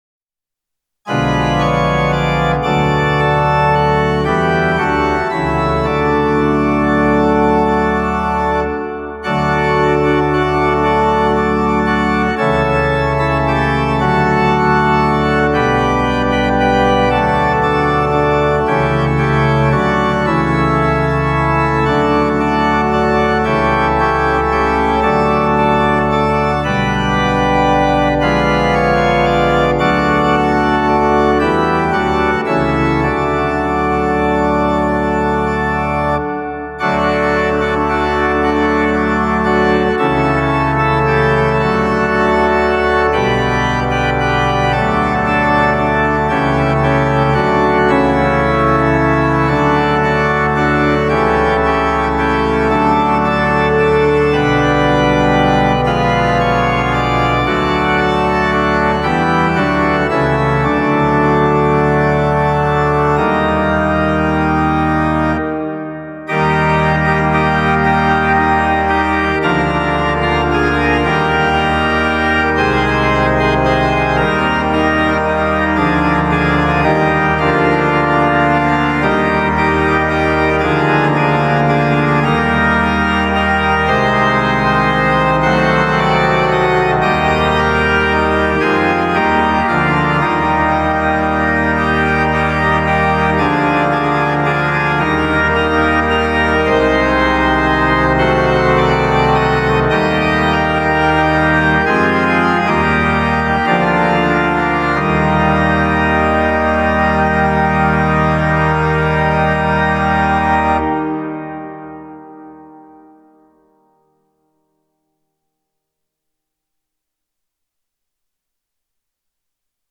Instrument: Viscount Cantorum Duo Plus
Music / Classical
hymn organ pipeorgan